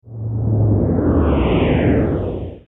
spaceship.wav